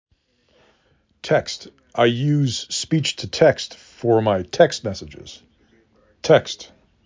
5 Phonemes
t e k s t